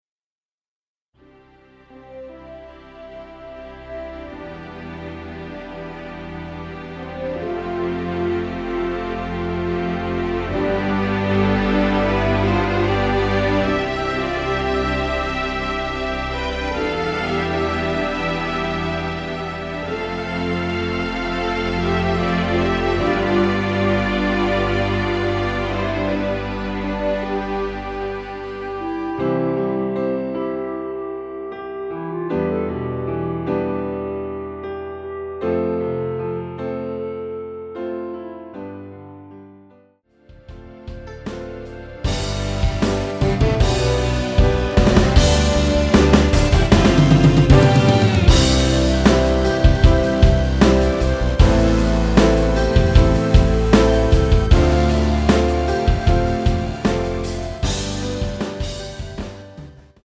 (-2 )멜로디 포함된 MR 입니다.(미리듣기 참조)
◈ 곡명 옆 (-1)은 반음 내림, (+1)은 반음 올림 입니다.
앞부분30초, 뒷부분30초씩 편집해서 올려 드리고 있습니다.